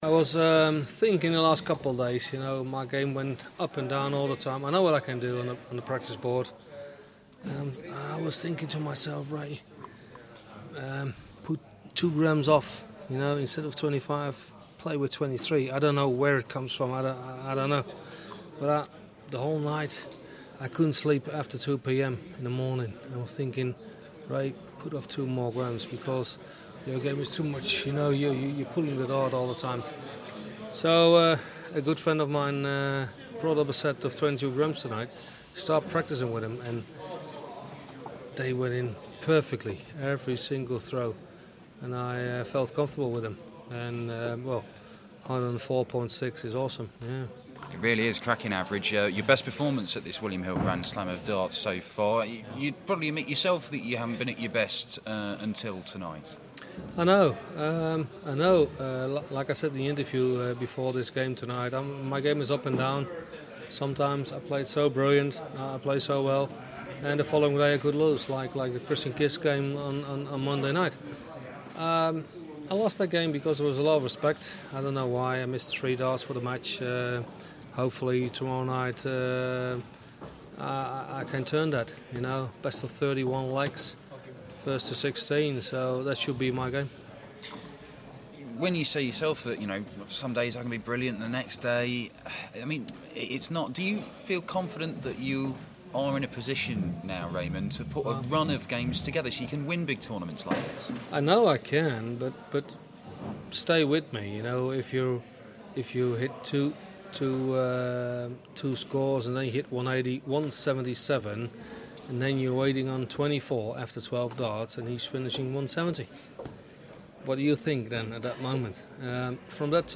William Hill GSOD - Barney Interview (Last 16)